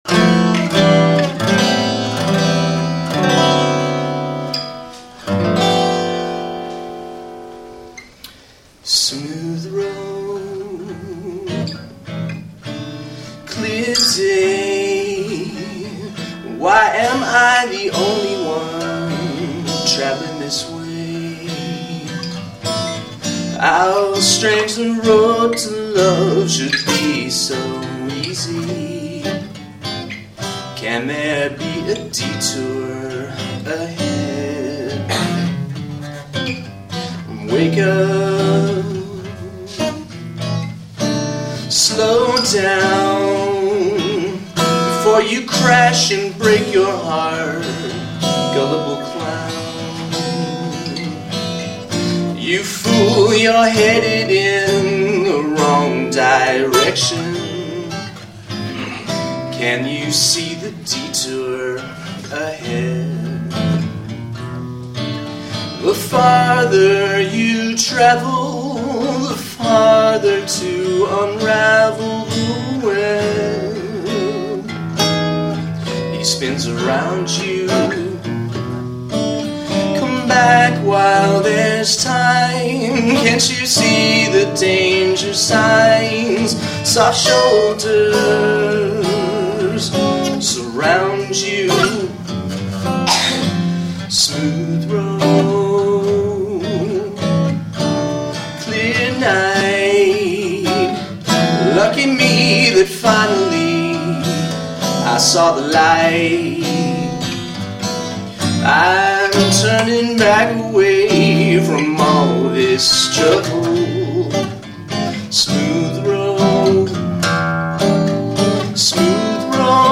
Live @ Los Angeles City College
jazz song